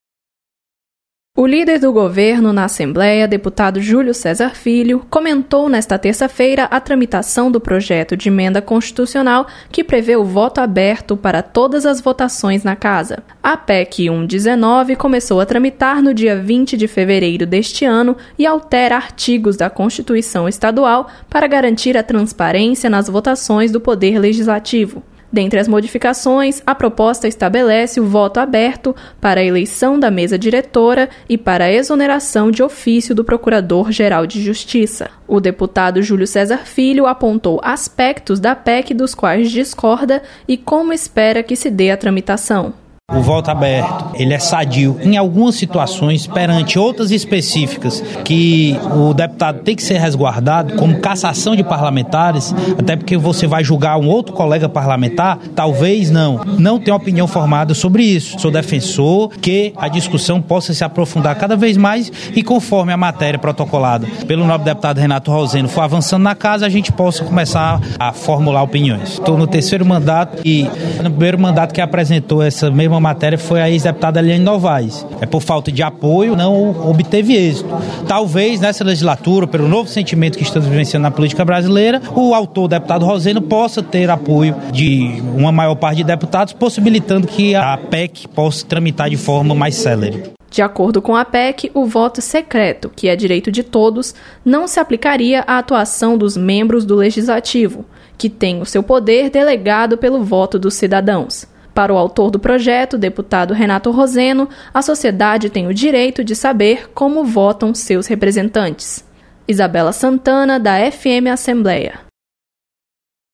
Deputado comenta tramitação de PEC que estabelece voto aberto na Assembleia.